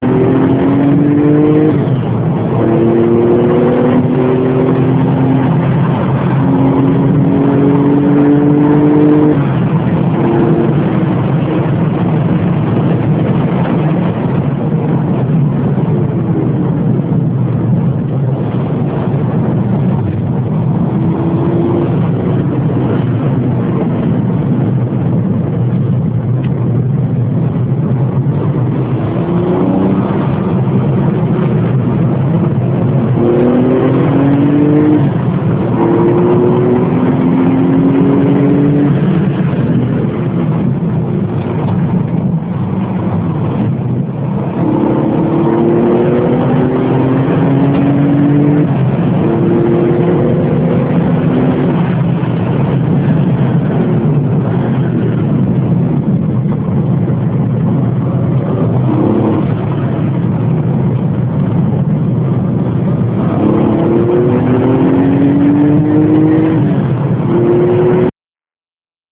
A bord d'une 965 Turbo 3,6